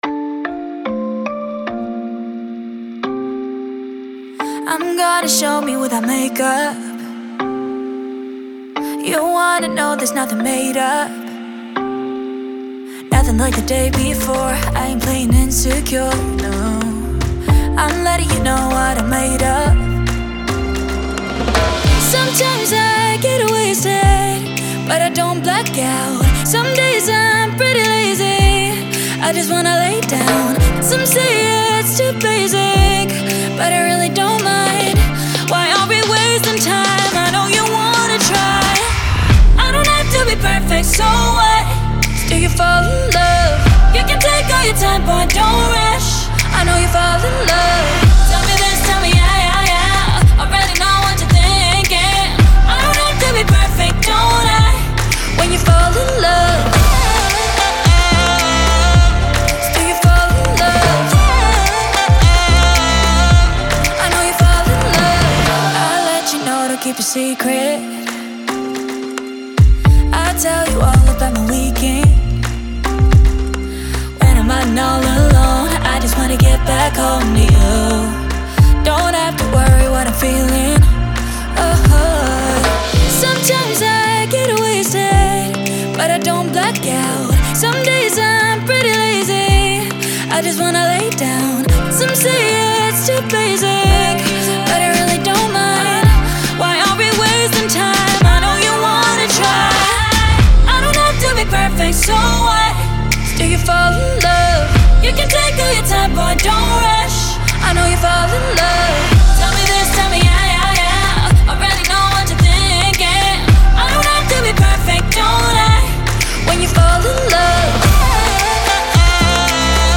Звучание трека отличается мелодичностью и душевностью